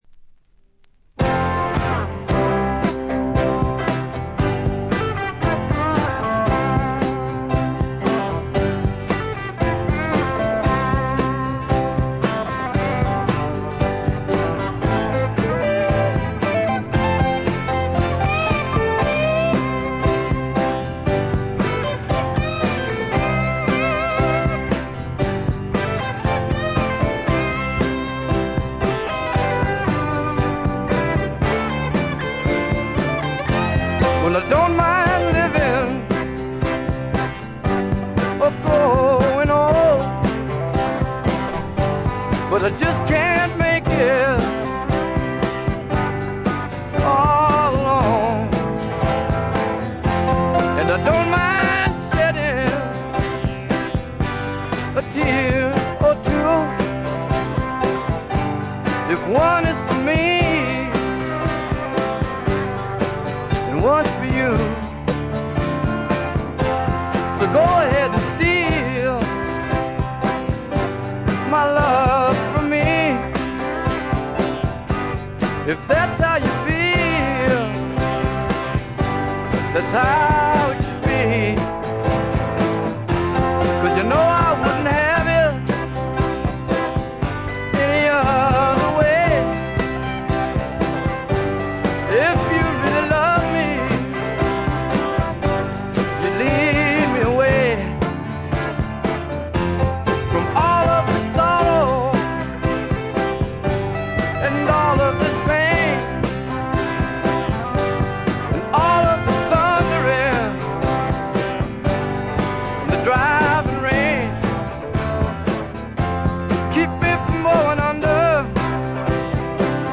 中身はヴォーカルも演奏も生々しくラフなサウンドがみっちり詰まっている充実作で、名盤と呼んでよいでしょう。
試聴曲は現品からの取り込み音源です。